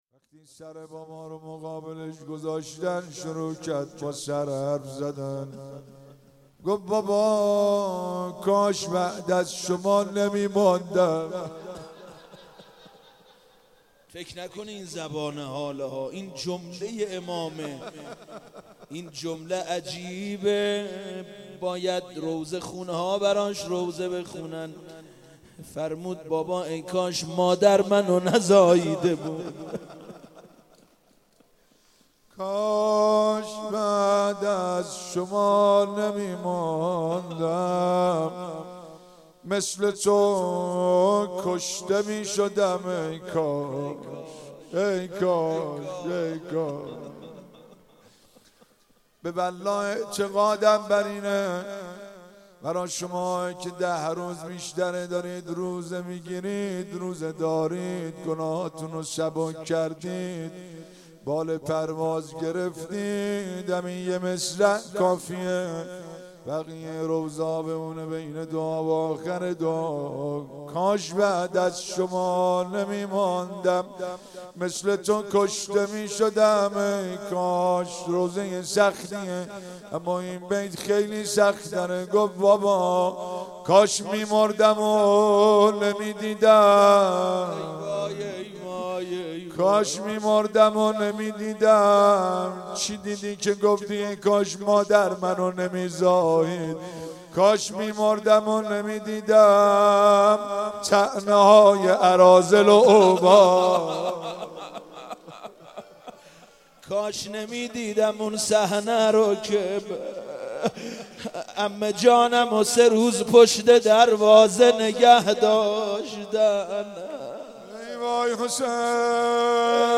دعای ابوحمزه ثمالی و روضه سیدالشهدا علیه السلام